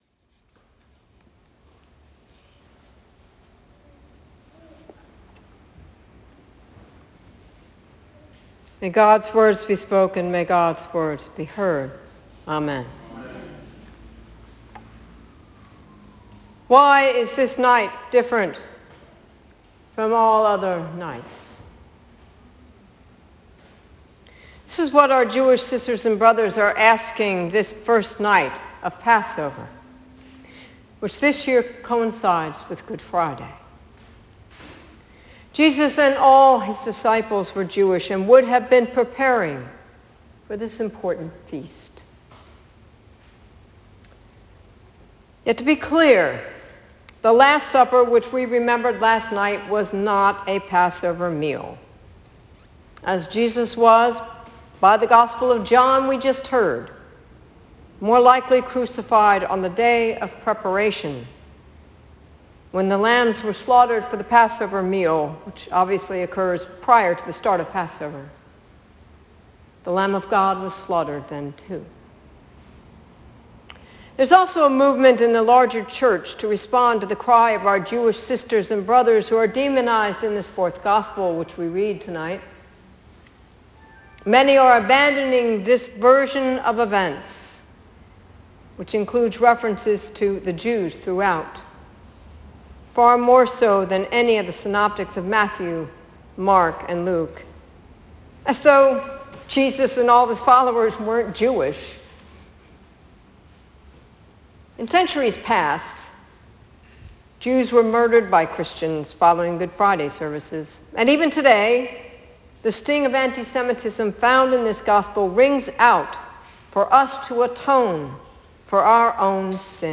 Sermon Podcast
Good Friday